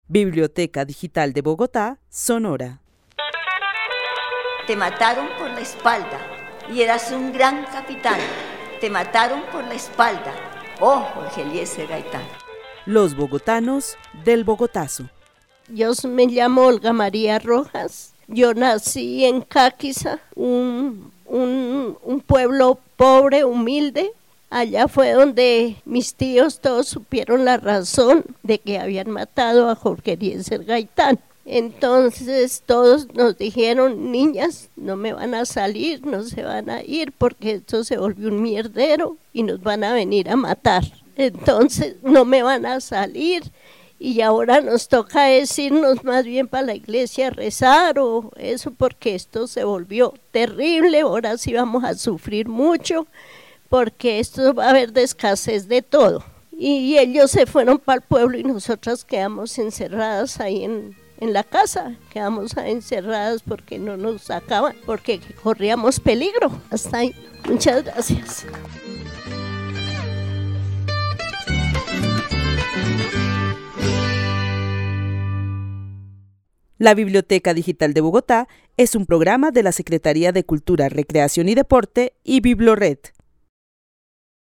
Narración oral de los hechos sucedidos el 9 de abril de 1948.
El testimonio fue grabado en el marco de la actividad "Los bogotanos del Bogotazo" con el club de adultos mayores de la Biblioteca El Tunal.